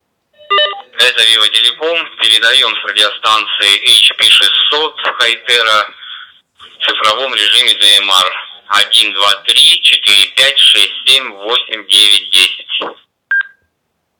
Модуляция в цифровом режиме DMR:
hp600-tx-digital.wav